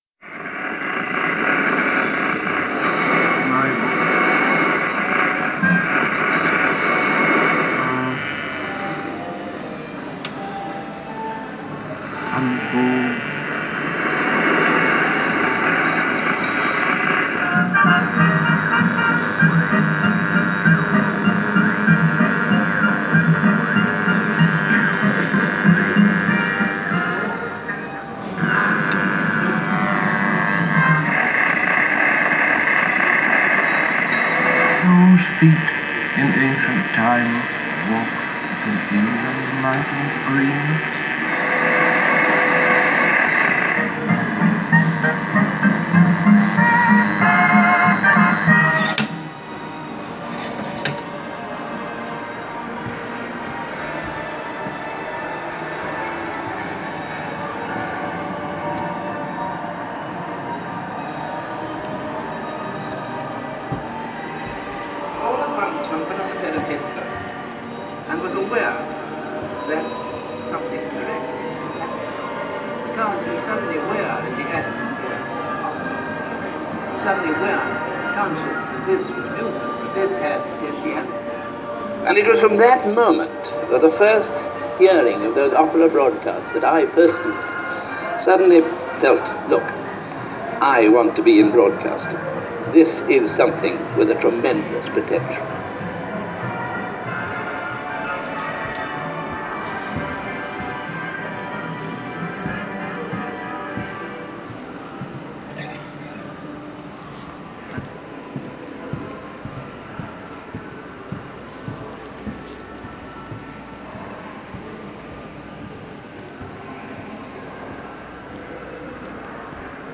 The Broadcast Zone in London's Science Museum - an interactive gallery charting the beginnings of global broadcast. Here you can hear snippets of early radio, Morse code and other communications as we tour the gallery.